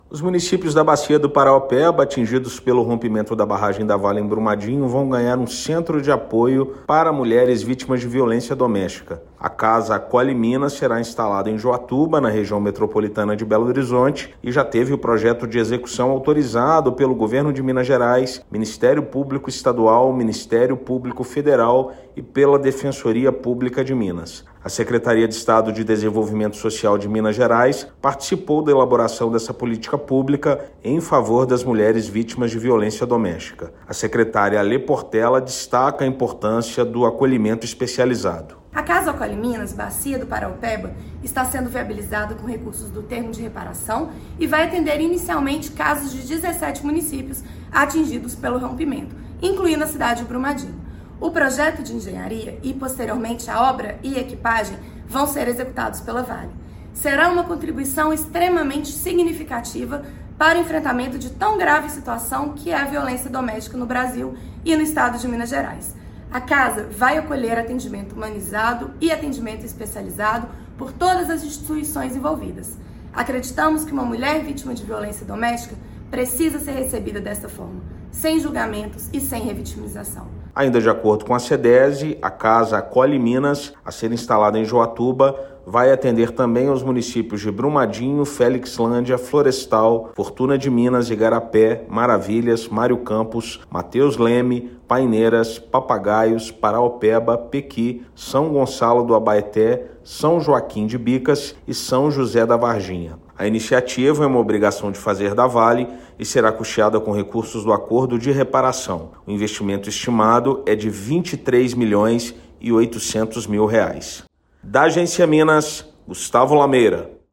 [RÁDIO] Reparação Brumadinho: região da bacia do Paraopeba vai receber centro de apoio às mulheres em situação de violência doméstica
Governo de Minas e instituições de Justiça autorizaram o início da construção da Casa Acolhe Minas – Bacia do Paraopeba, em Juatuba, para atender demandas de 17 municípios atingidos pelo rompimento das barragens na mina Córrego do Feijão. Ouça matéria de rádio.